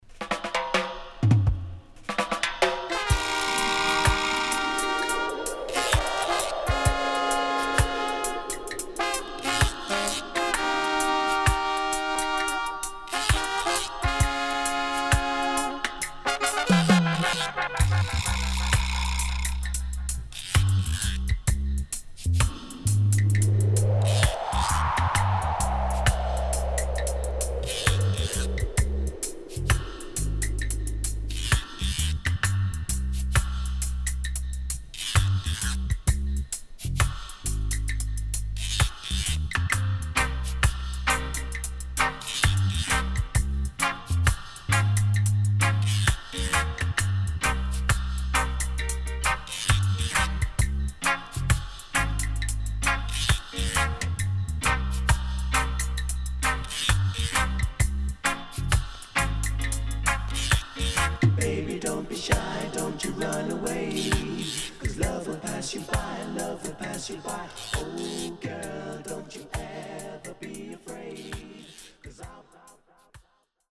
Nice Vocal & Wicked Dubwise
SIDE A:序盤に少しプチノイズ入りますが、他は概ね良好です。